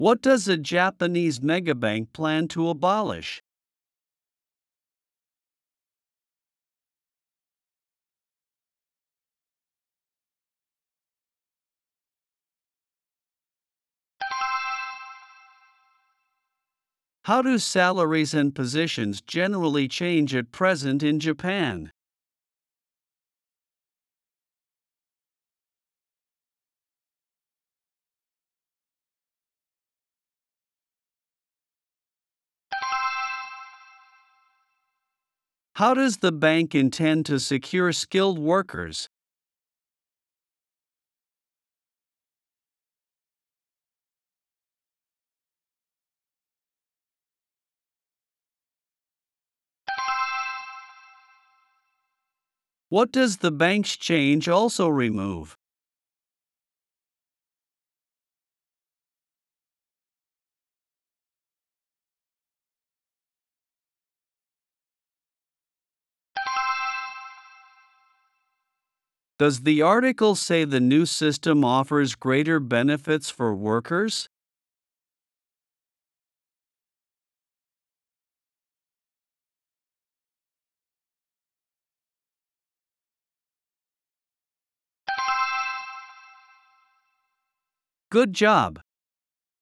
プレイヤーを再生すると英語で5つの質問が1問ずつ流れ、10秒のポーズ（無音部分）があります。
10秒後に流れる電子音が終了の合図です。
10秒スピーチ質問音声